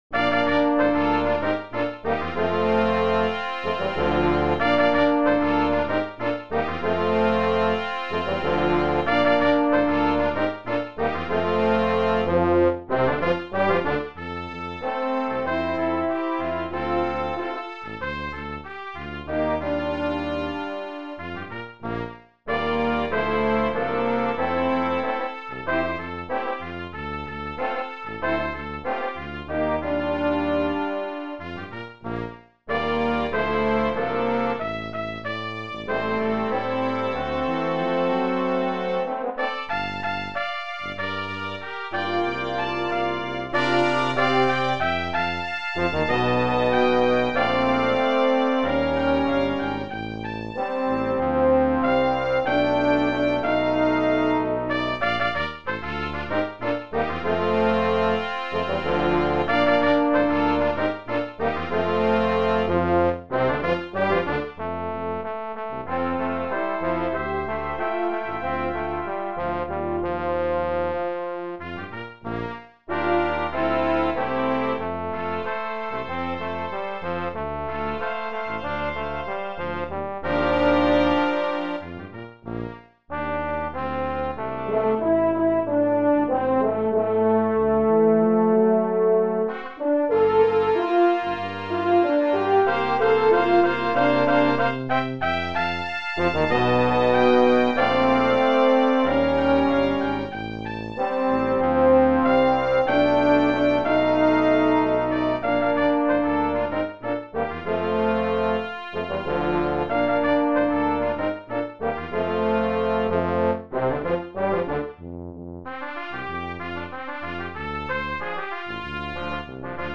Brass Quintet
arranged for brass quintet